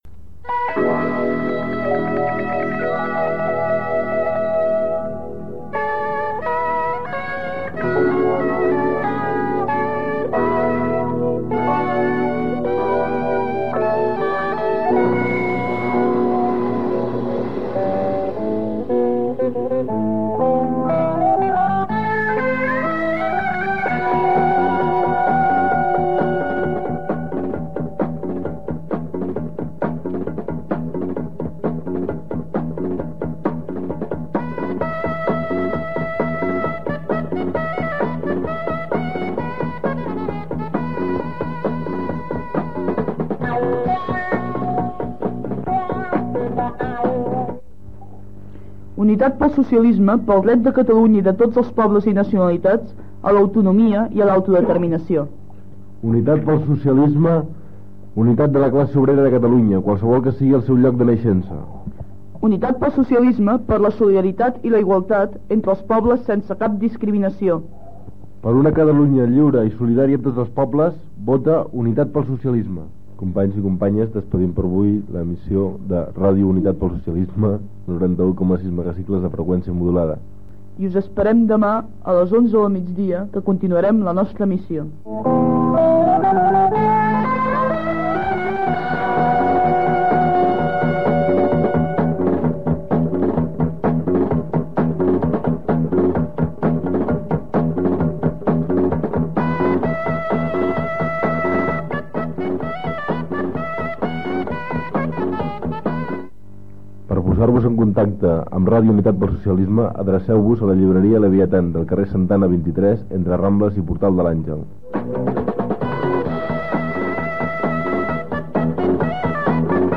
Lemes de campanya de les eleccions al Parlament de Catalunya i fi d'emissió.
FM